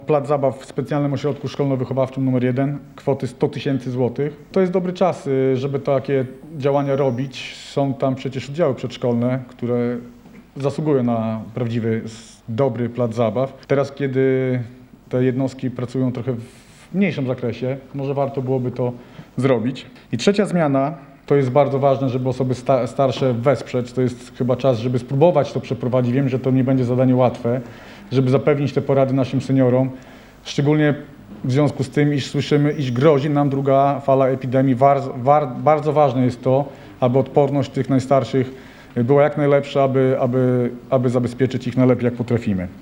O przeznaczenie pieniędzy na wspomniane cele wnioskował Sławomir Sieczkowski, radny ugrupowania „Mieszkańcy Suwałk”.